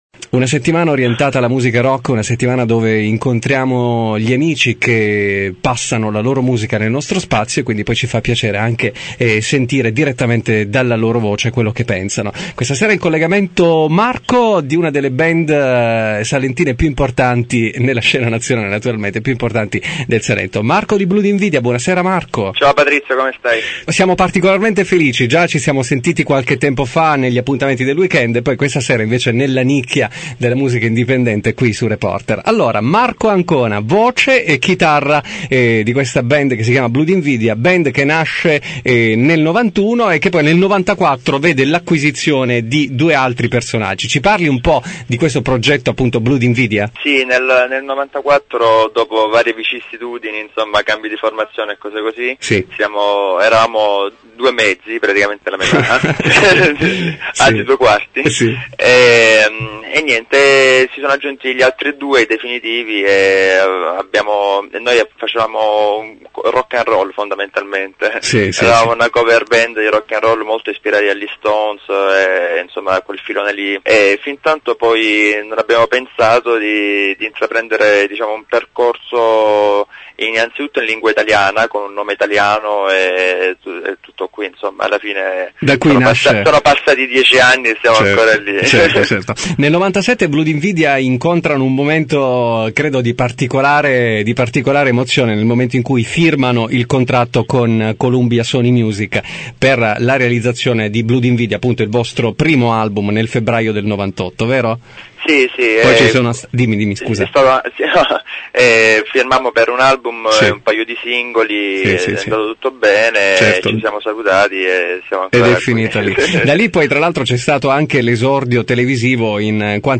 Intervista audio